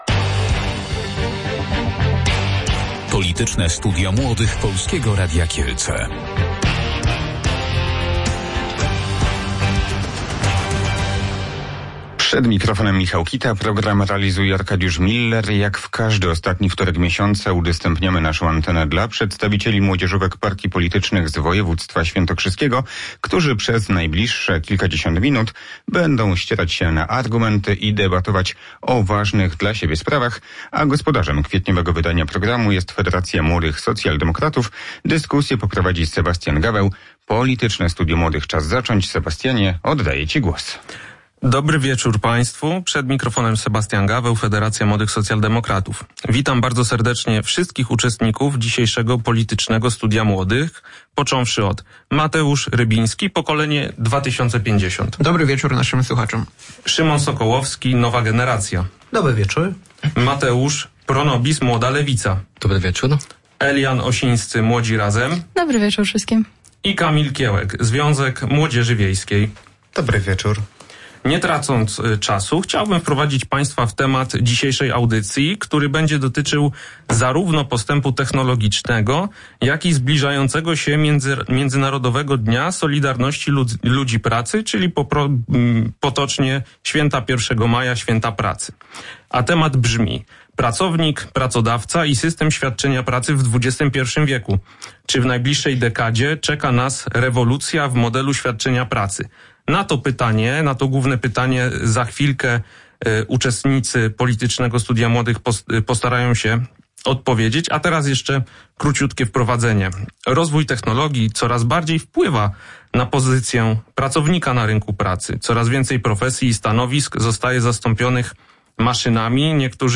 Pracownik, pracodawca i system świadczenia pracy w XXI wieku – czy w najbliższej dekadzie czeka nas rewolucja w modelu świadczenia pracy? Czy rozwój technologii jest szansą na zmianę dotychczasowego modelu pracy na mniej obciążający dla ludzi, czy może zagrożeniem i prostą drogą do masowego bezrobocia? – między innymi o tym dyskutowali uczestnicy wtorkowego (25 kwietnia) wydania Politycznego Studia Młodych Radia Kielce.